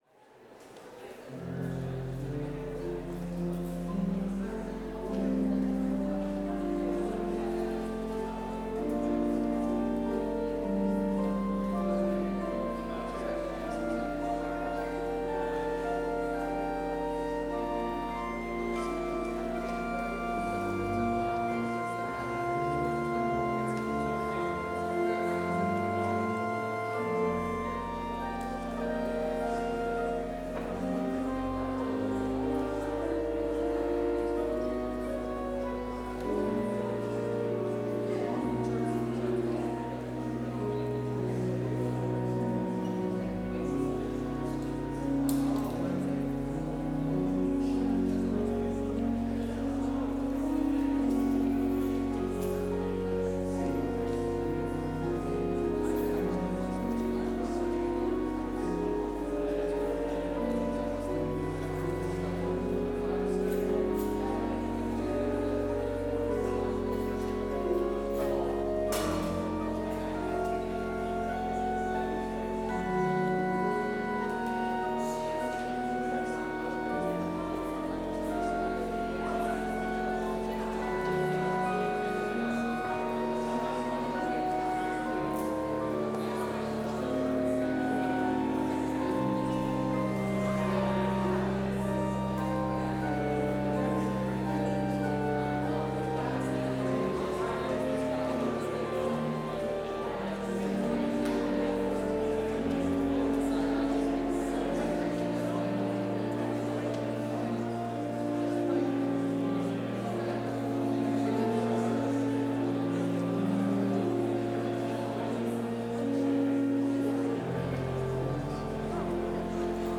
Complete service audio for Chapel - Wednesday, September 3, 2025